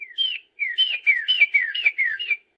sounds_bird_03.ogg